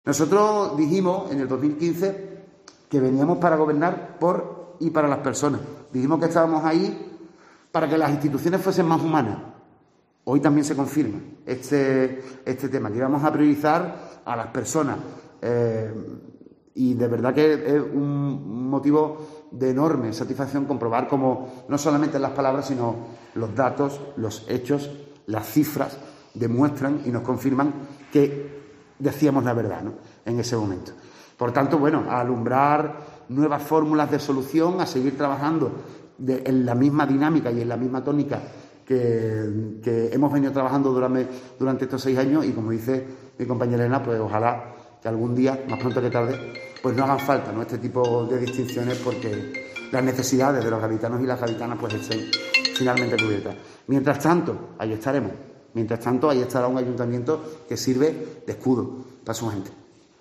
José María González, alcalde de Cádiz, sobre asuntos sociales